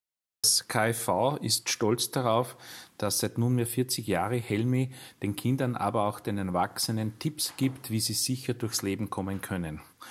O-Ton